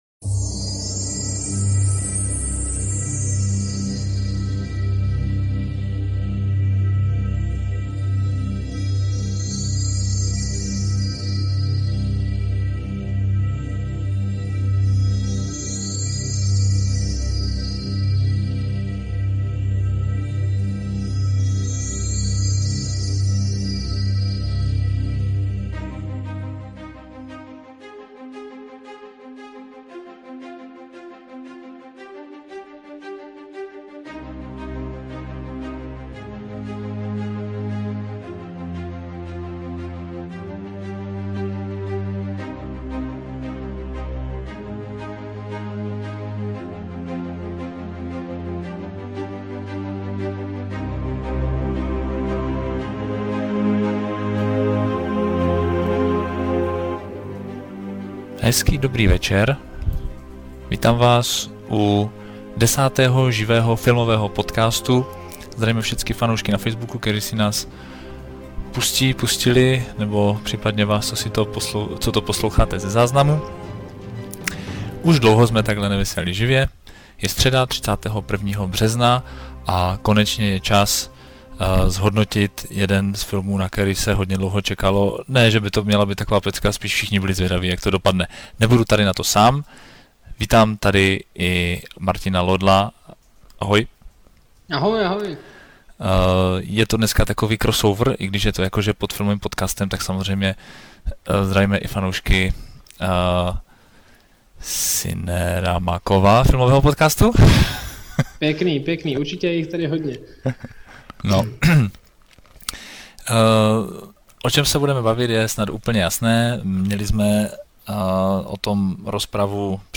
Dlouho jsme si nedali živé vysílání. Hodnocení snyderovy verze Ligy Spravedlnosti ale nesneslo dalšího odkladu.